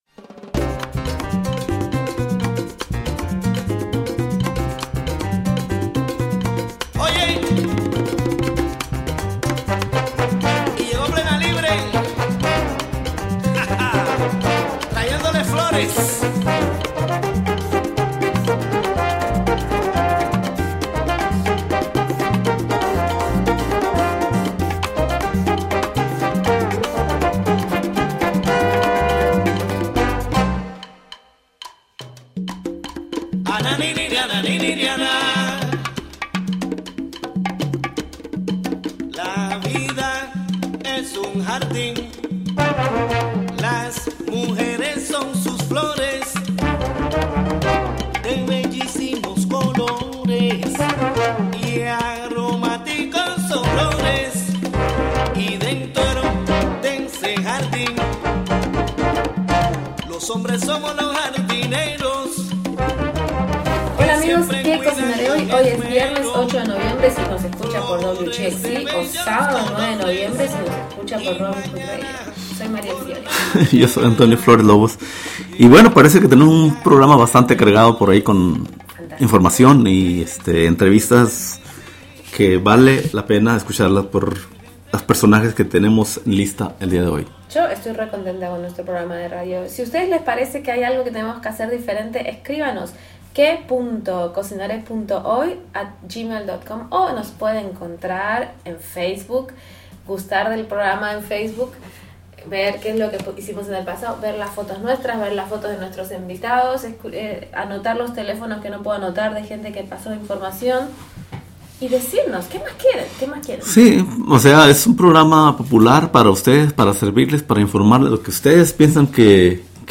broadcasts
Highlights from the latest issue of "La Voz" magazine, a conversation with documentary filmmaker